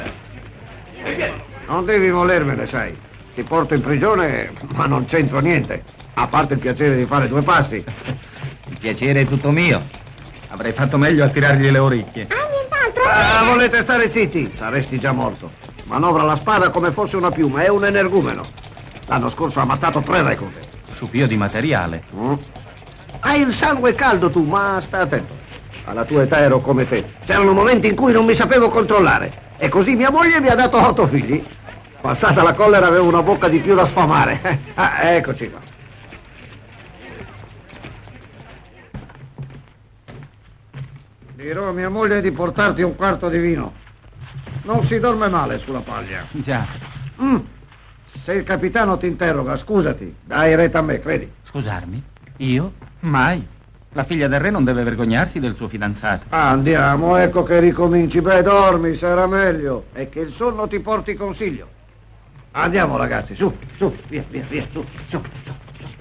"Fanfan le Tulipe", in cui doppia Olivier Hussenot.